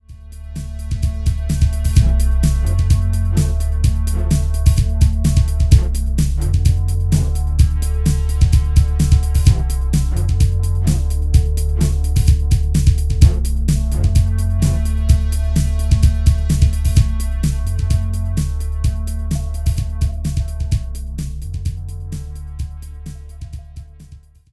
Audiorecording, Musik & Sounddesign